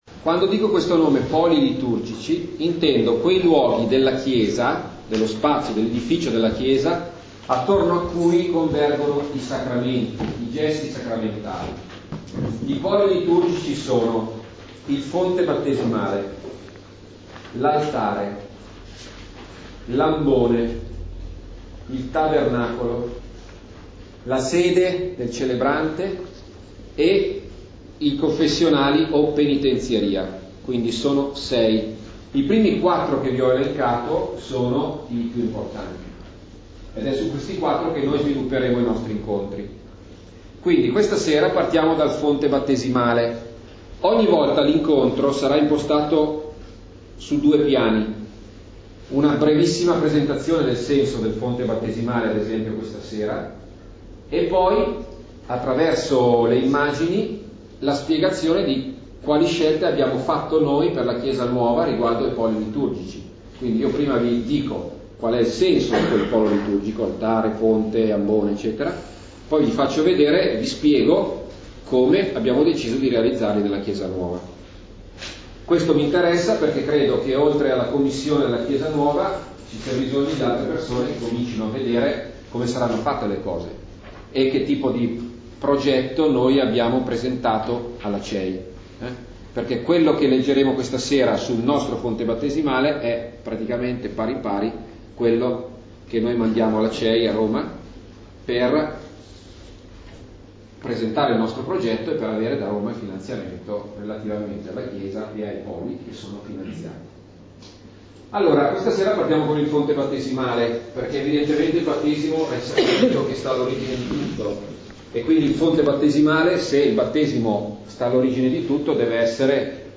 Il fonte battesimale Registrazione della serata del 6 novembre 2014